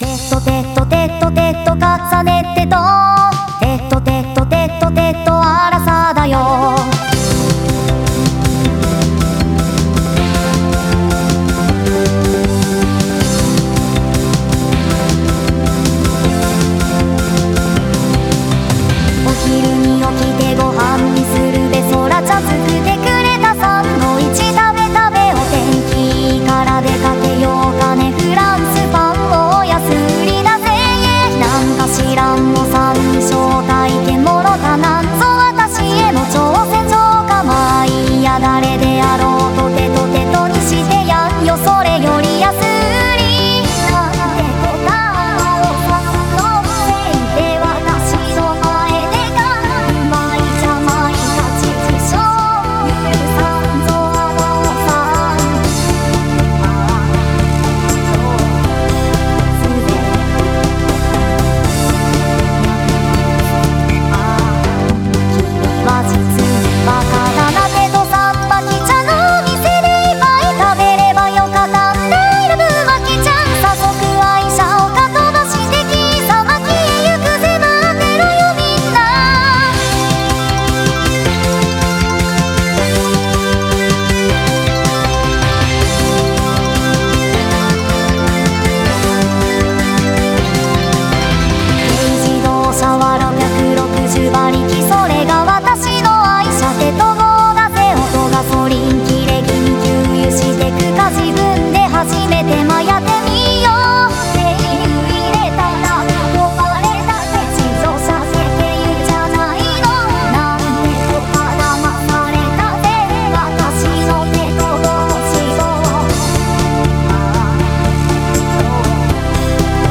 近年の合成音声はクオリティが高く、まるで人間のように自然な声で歌います。
Vocal Synthesizer